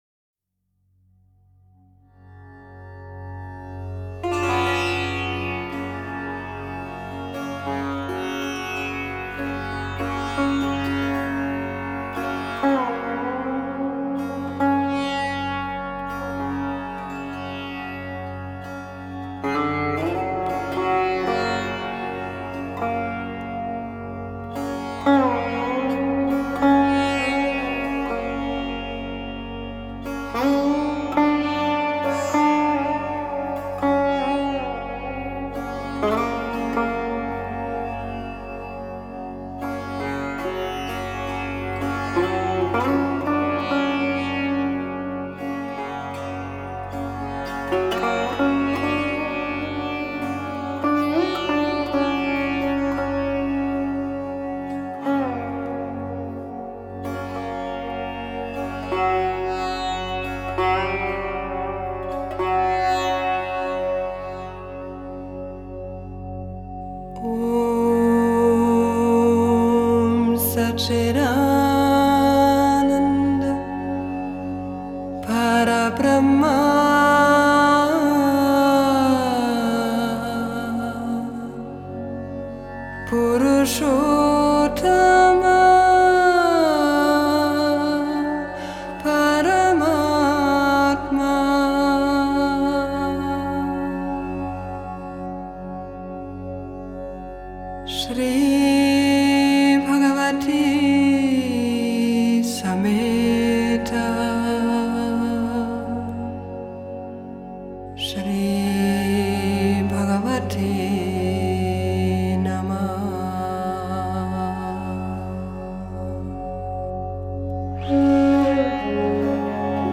Медитативная музыка